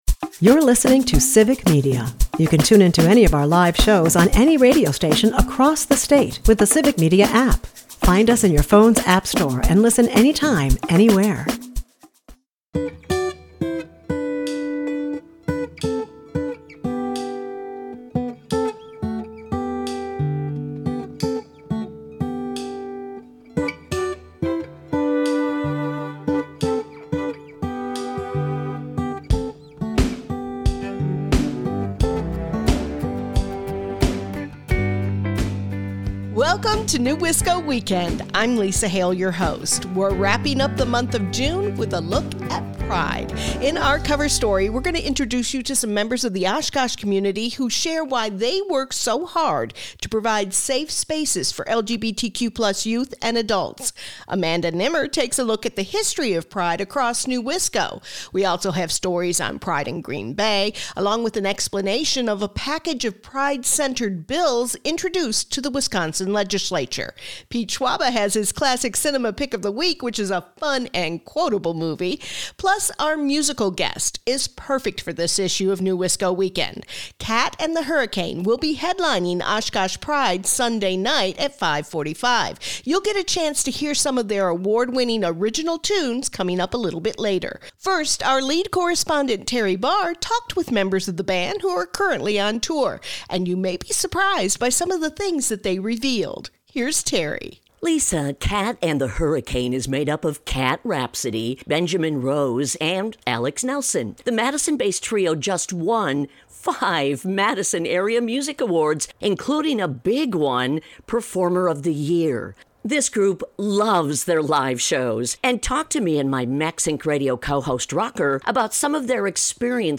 Madison’s Kat and the Hurricane open the issue with a discussion of being an all queer and trans band and closes the issue with a couple of great songs!